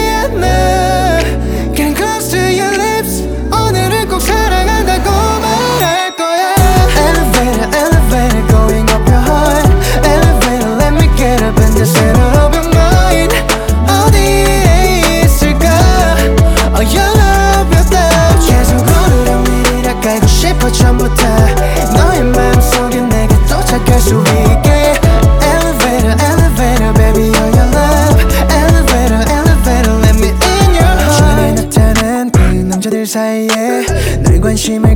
R B Soul
Жанр: R&B / Соул